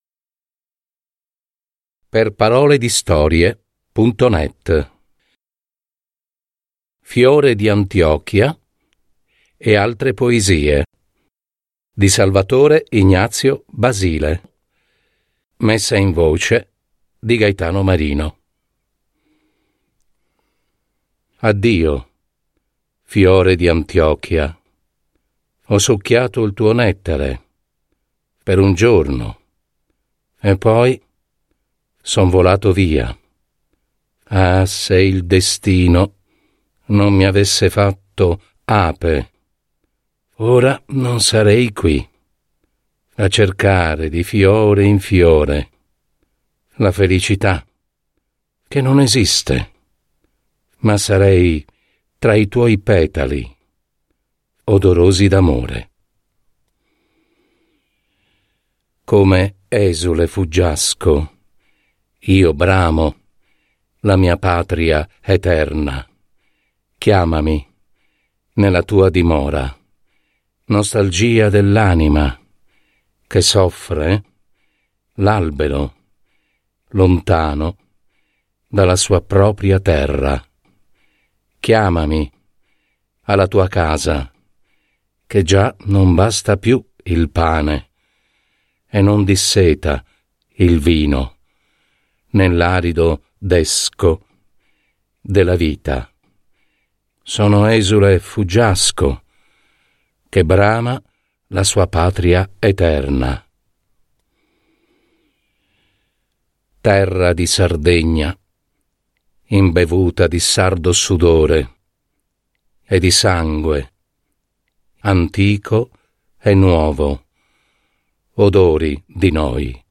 Drammaturgia del suono e messa in voce